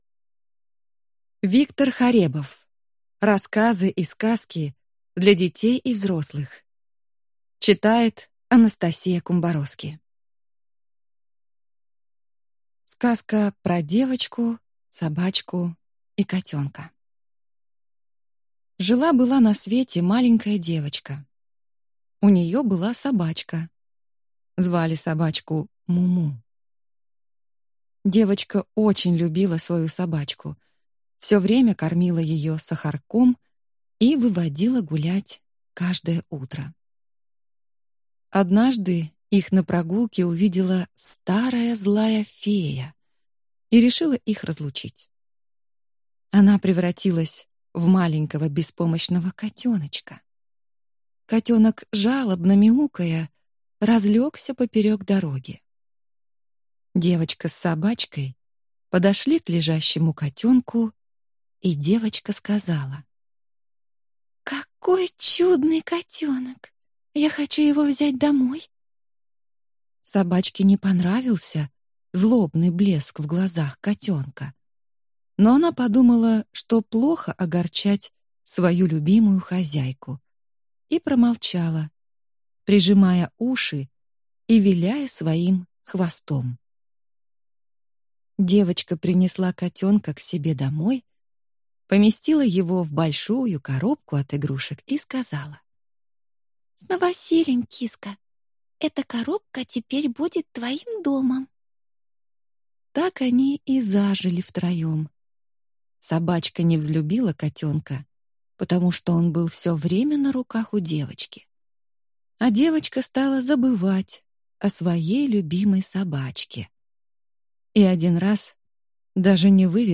Аудиокнига Рассказы и сказки для детей и взрослых | Библиотека аудиокниг